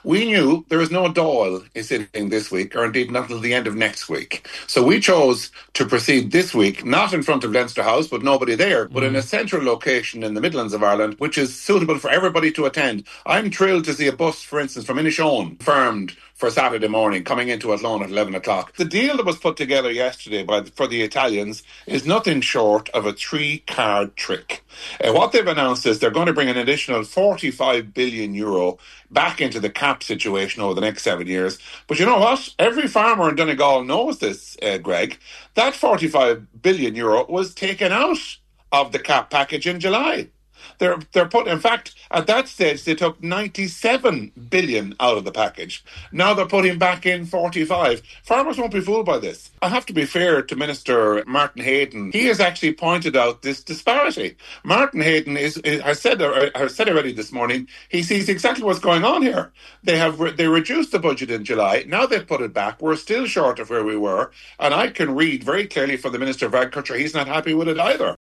MEP Ciaran Mulloley called for a high turnout on Saturday.